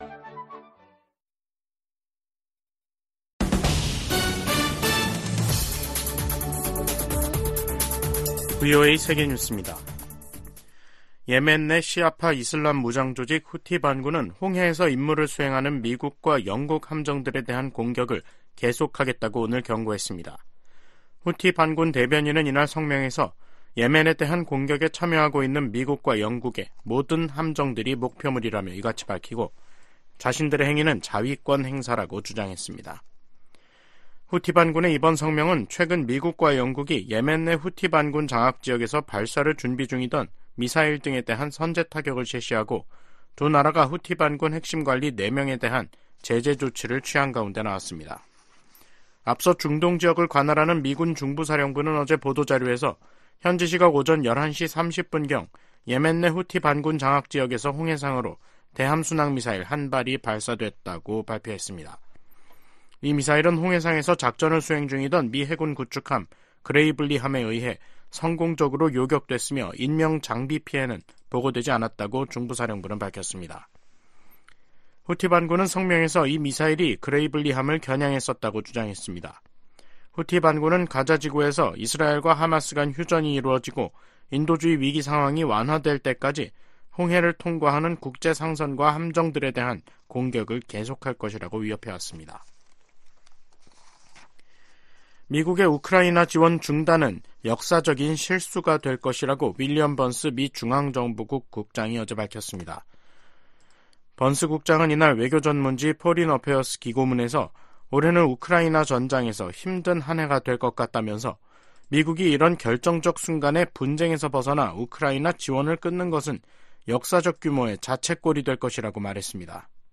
VOA 한국어 간판 뉴스 프로그램 '뉴스 투데이', 2024년 1월 31일 2부 방송입니다. 윤석열 한국 대통령은 북한이 총선을 겨냥한 도발을 벌일 것이라며 총력 대비해야 한다고 강조했습니다. 미 공화당 하원의원들이 자동차업체 포드 사와 계약을 맺은 중국 업체들이 북한 정부 등과 연계돼 있다고 밝혔습니다.